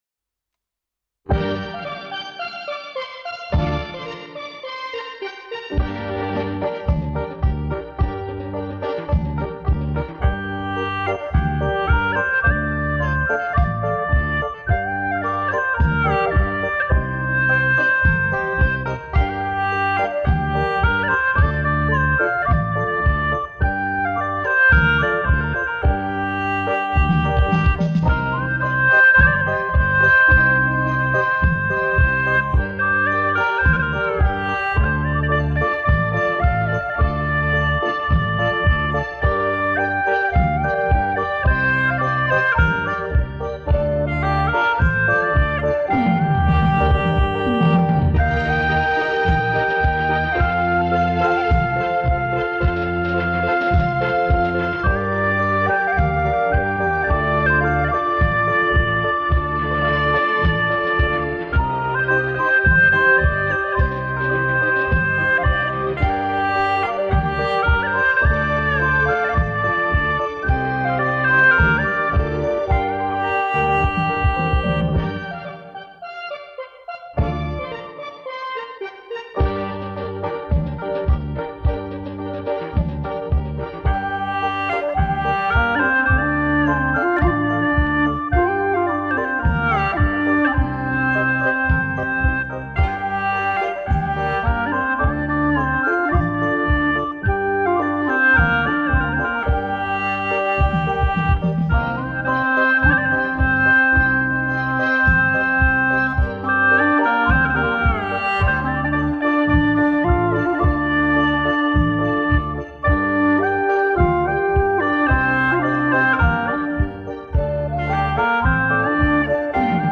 调式 : 降B 曲类 : 红歌